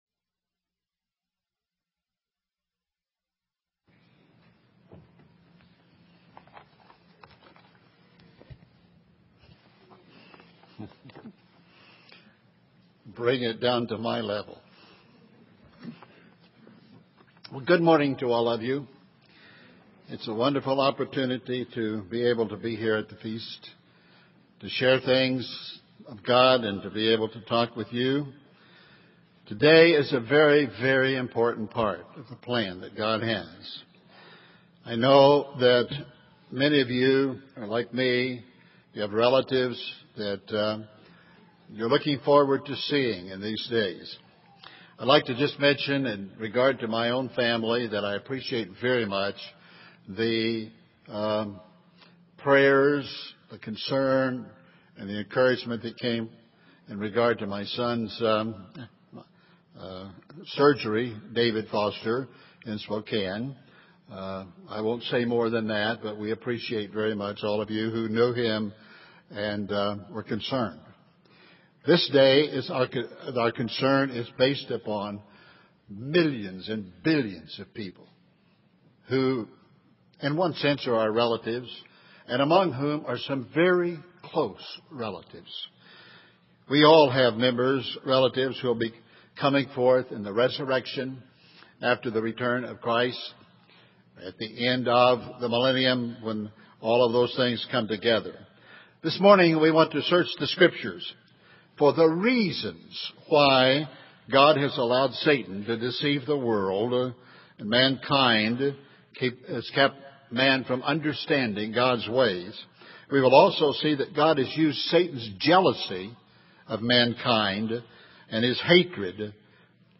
This sermon was given at the Bend, Oregon 2009 Feast site.